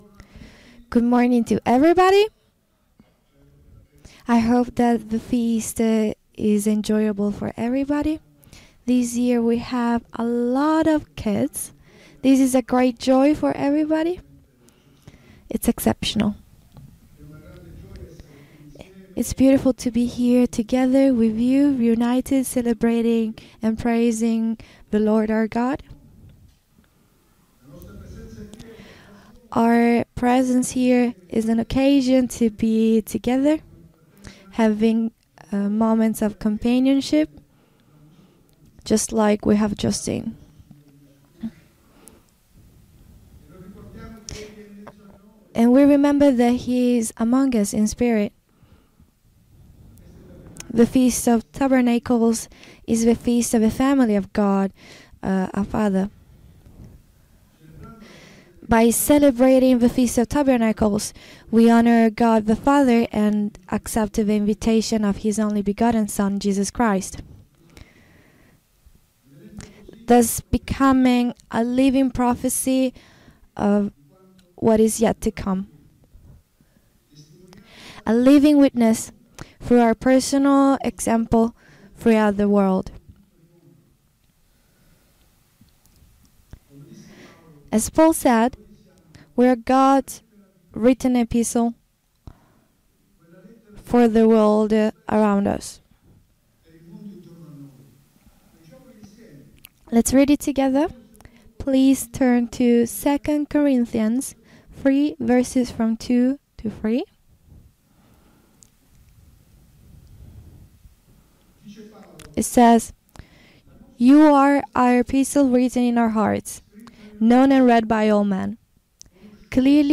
FoT 2024 Marina di Grosseto (Italy): 4th day
Sermons
Given in Milan